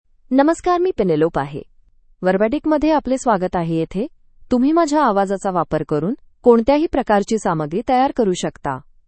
FemaleMarathi (India)
Penelope — Female Marathi AI voice
Voice sample
Female
Penelope delivers clear pronunciation with authentic India Marathi intonation, making your content sound professionally produced.